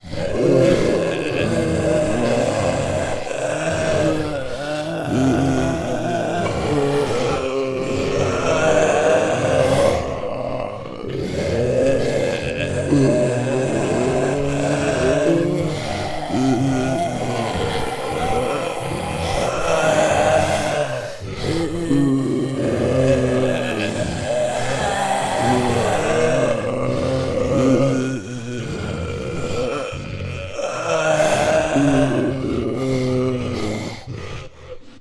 Zombie_01.wav